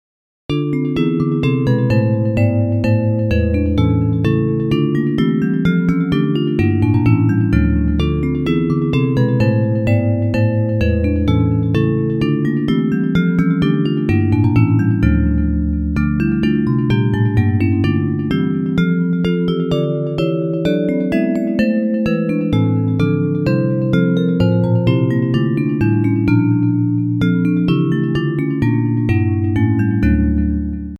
Bells Version